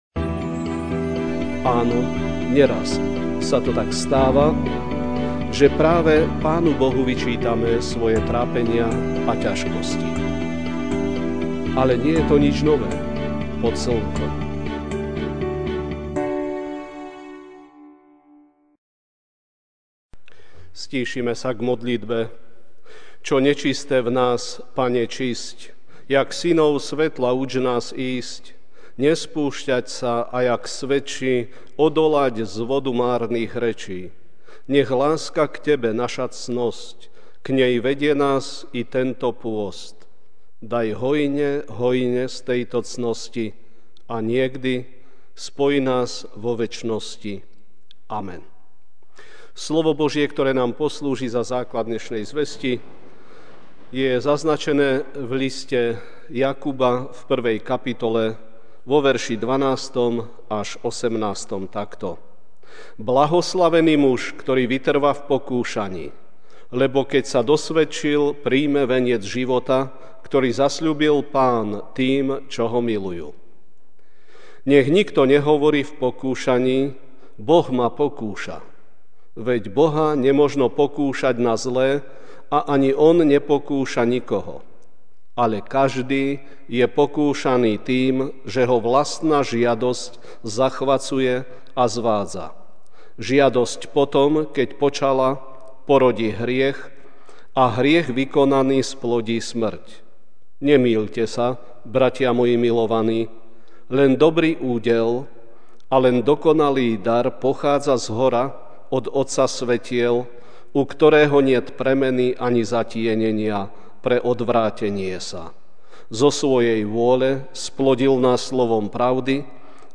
Večerná kázeň: Pokánie (Jk. 1, 12-15) Blahoslavený muž, ktorý vytrvá v pokúšaní, lebo keď sa osvedčil, prijme veniec života, ktorý zasľúbil (Pán) tým, čo Ho milujú.